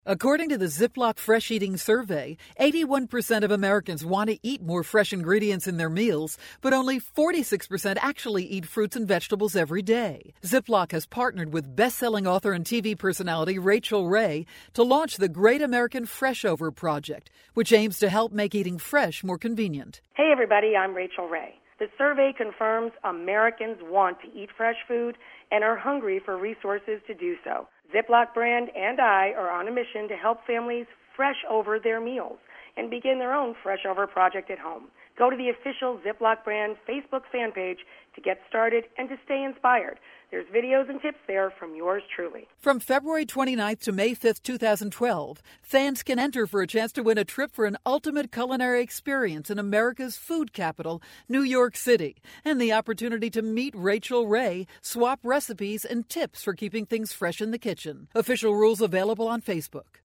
March 28, 2012Posted in: Audio News Release